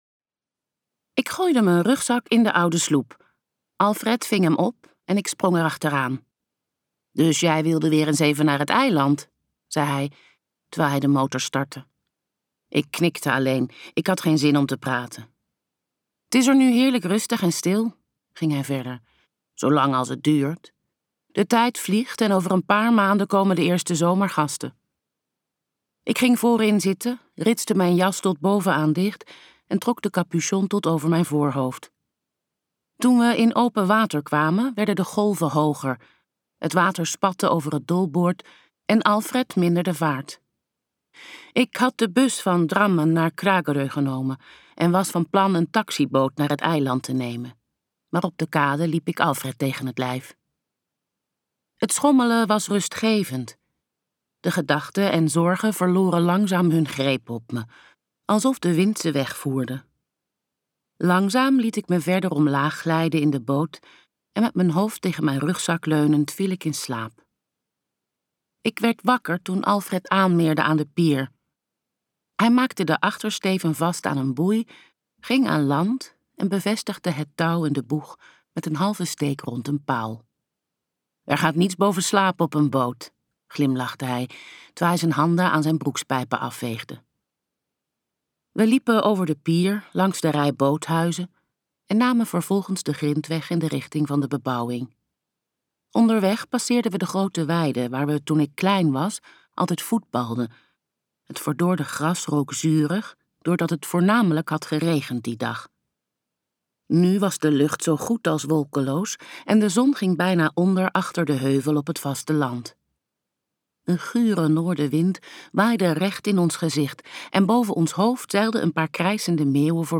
Ambo|Anthos uitgevers - Ze danste in de regen luisterboek